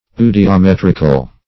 Search Result for " eudiometrical" : The Collaborative International Dictionary of English v.0.48: Eudiometric \Eu`di*o*met"ric\, Eudiometrical \Eu`di*o*met"ric*al\, a. Of or pertaining to a eudiometer; as, eudiometrical experiments or results.